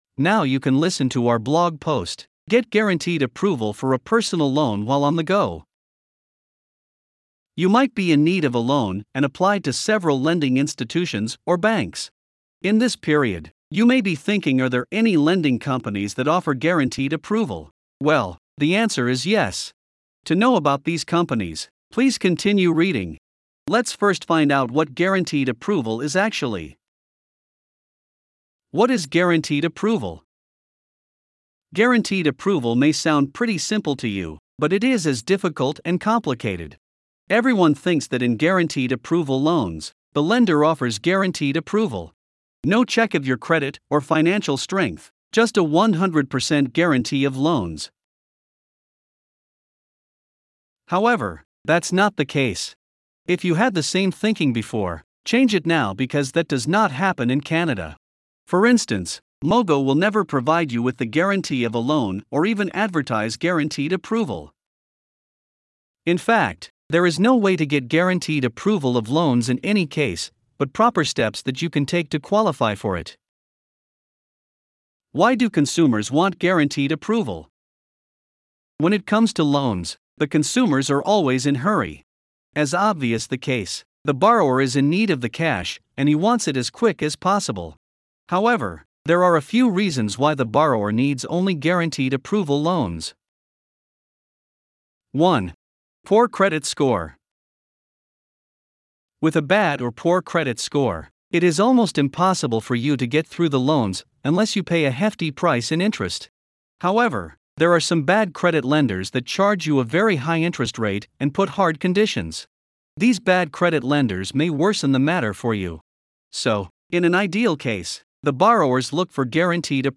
Voiceovers-Voices-by-Listnr_2.mp3